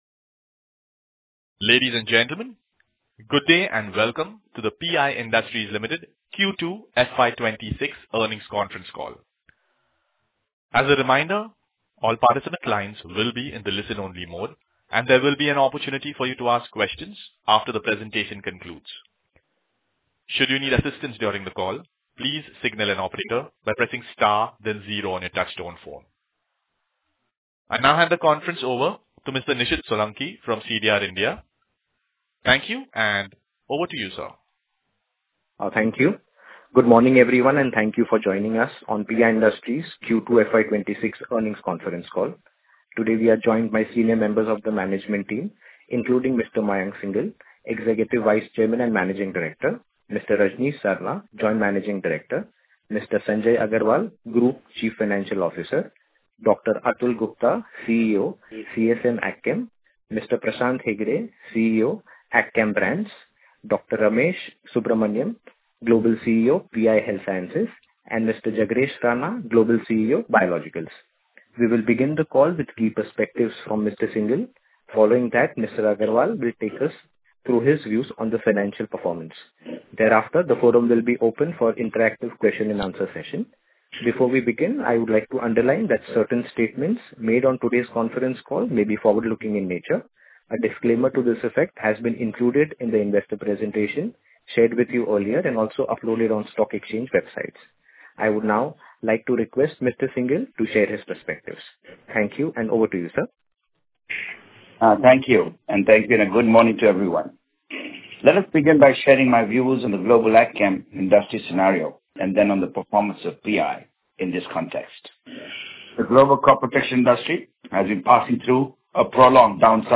Audio Recording of Q2 FY26 Earnings conference call